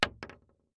Bullet Shell Sounds
shotgun_wood_4.ogg